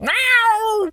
cat_scream_08.wav